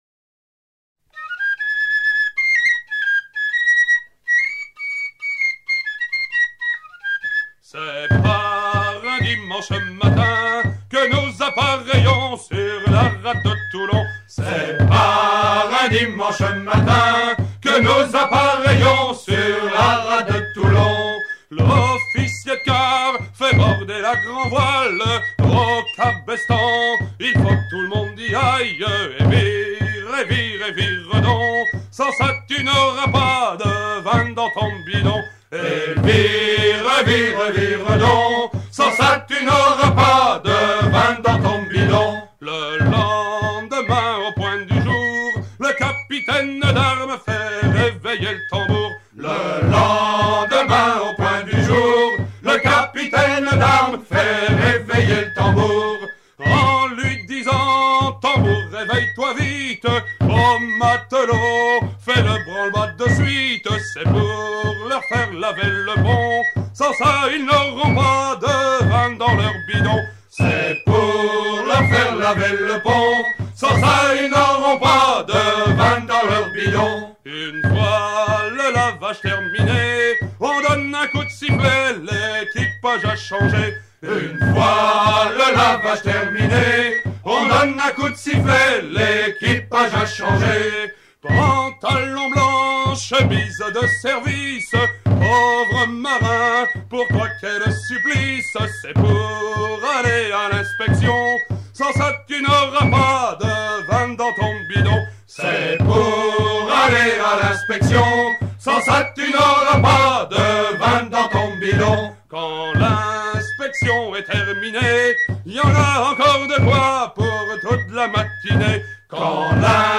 Fonction d'après l'analyste gestuel : à virer au cabestan
Genre strophique
Pièce musicale éditée